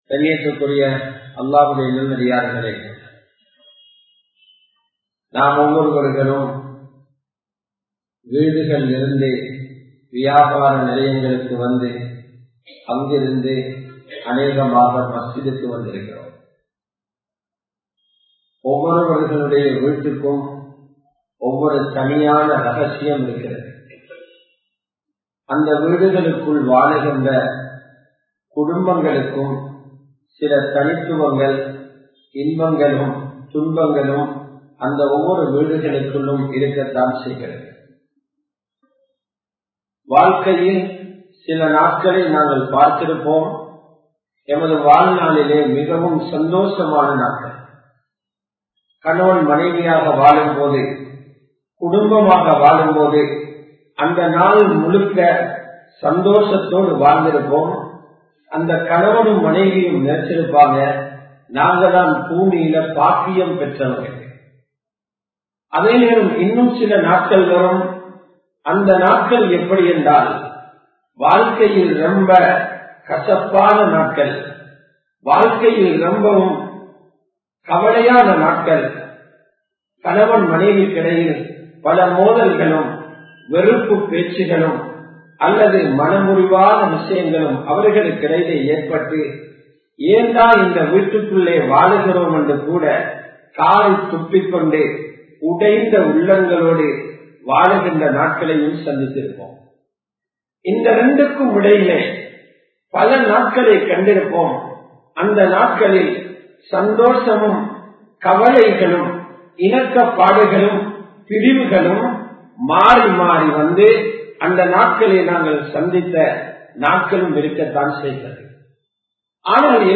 சிறந்த மனிதனின் பண்புகள் | Audio Bayans | All Ceylon Muslim Youth Community | Addalaichenai
Colombo 11, Samman Kottu Jumua Masjith (Red Masjith)